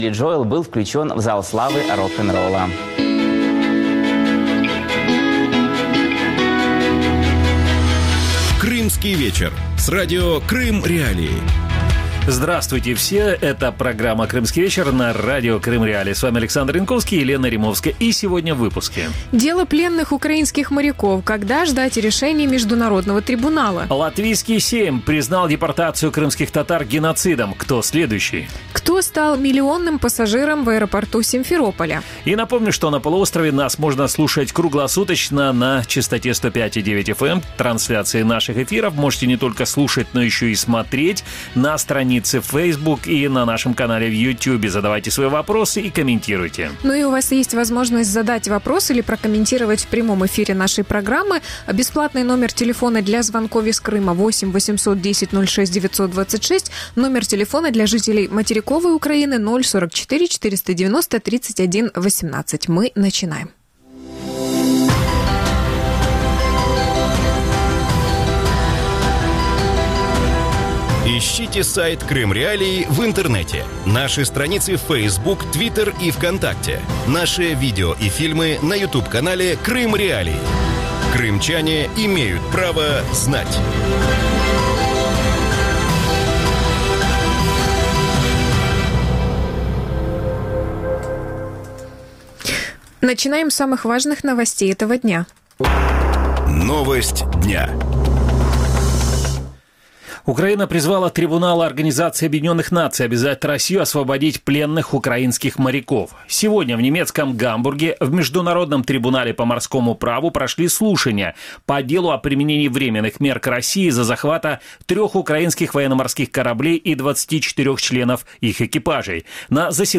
российский политолог
украинский юрист-международник